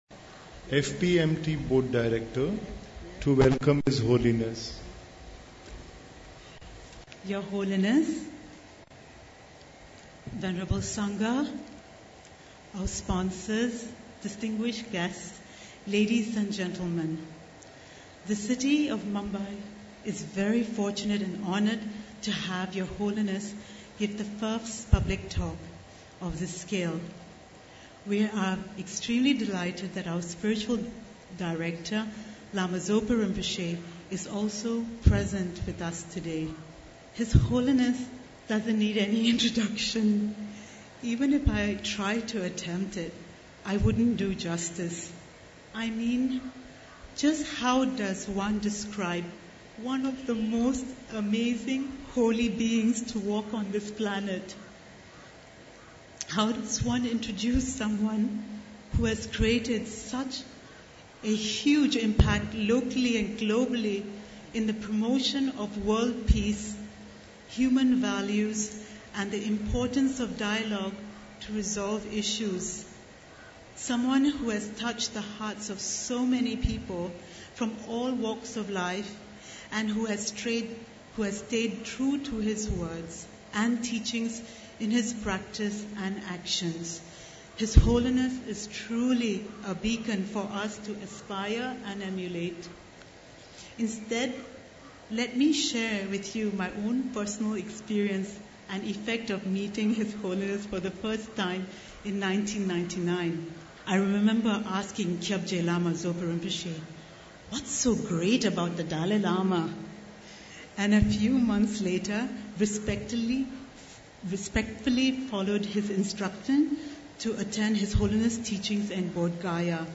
FPMT Audio Teachings Peace through Inner Peace The teachings from His Holiness the Dalai Lama in Mumbai January 31 2007 ENGLISH - Low Res Version - 16k ENGLISH - High Res Version - 32k Peace